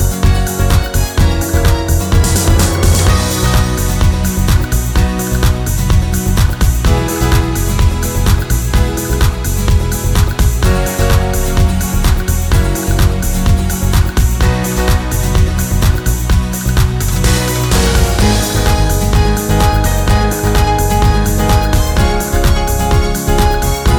Dance Mix - no Backing Vocals Pop (1980s) 4:29 Buy £1.50